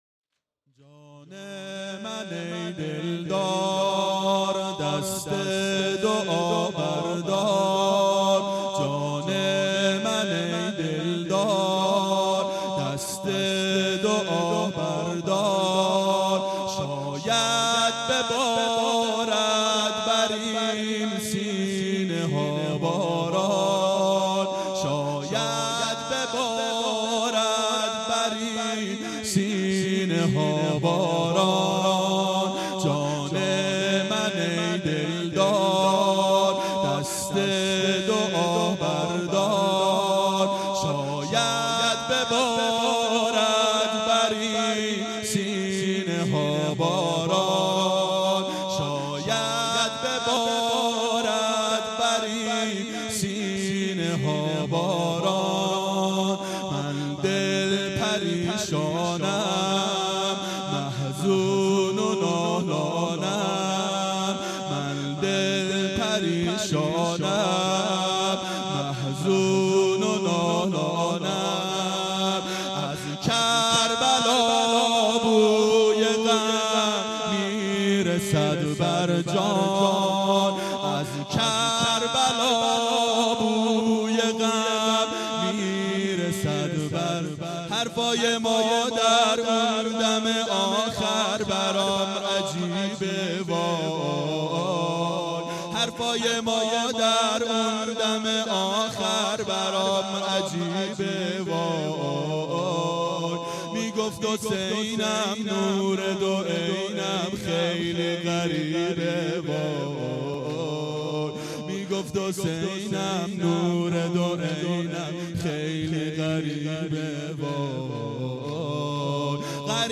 دهه اول صفر سال 1391 هیئت شیفتگان حضرت رقیه سلام الله علیها (شب اول)